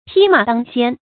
匹馬當先 注音： ㄆㄧˇ ㄇㄚˇ ㄉㄤ ㄒㄧㄢ 讀音讀法： 意思解釋： 一馬當先。指沖鋒在前。